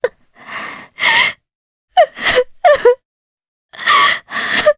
Juno-Vox_Sad_jp.wav